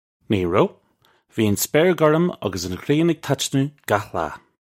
Pronunciation for how to say
Nee roe. Vee an spare gurrum uggus un ghree-un ig tatch-noo gakh lah. (U)
This is an approximate phonetic pronunciation of the phrase.